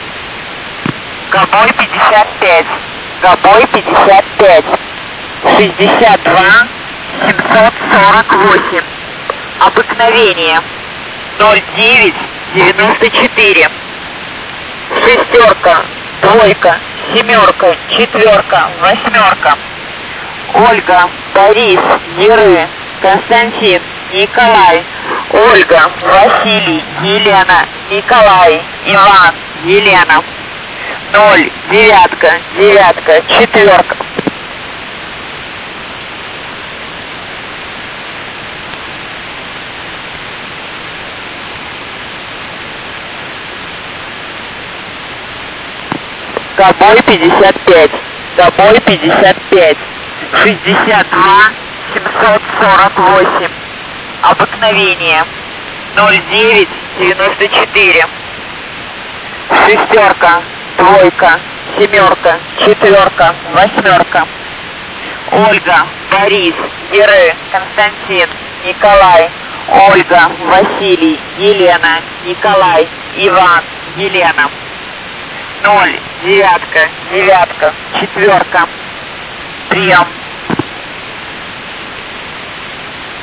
• J3E (USB)
Monolit for Goboj-55.